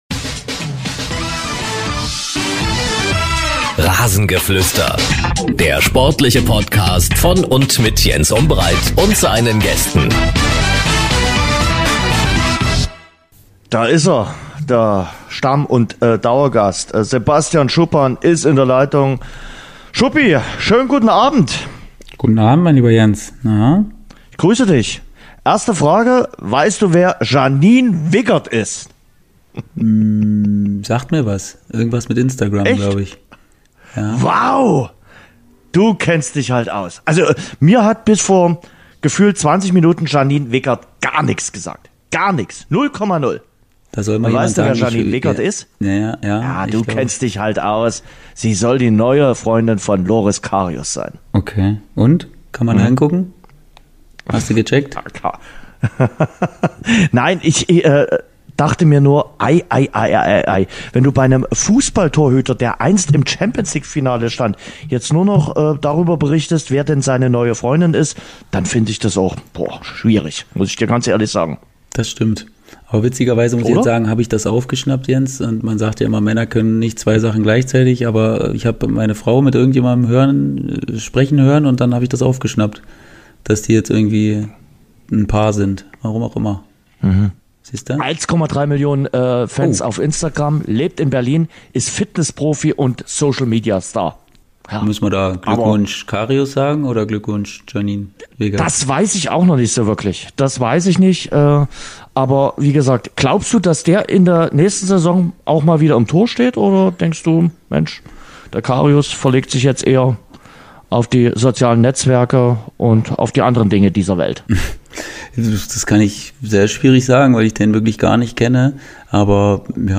Wolff Fuss im Interview Mit Fußball-Kommentator Wolff Fuss